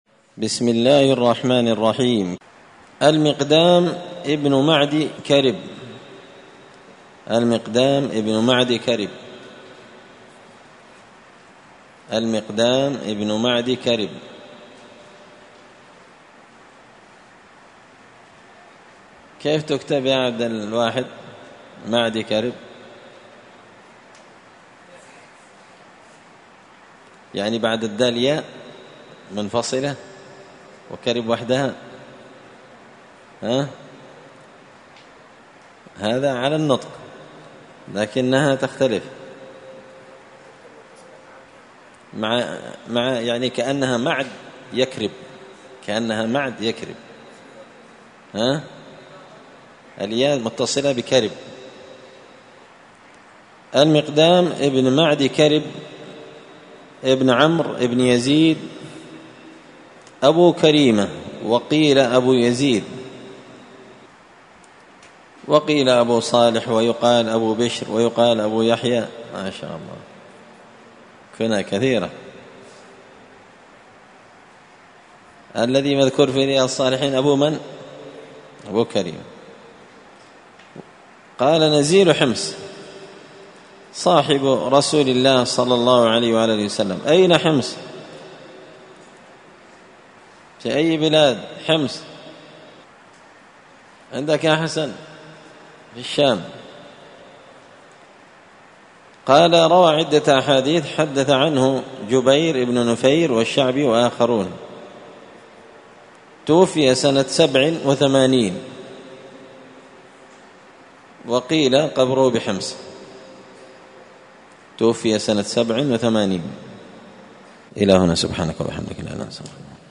قراءة تراجم من تهذيب سير أعلام النبلاء
مسجد الفرقان قشن المهرة اليمن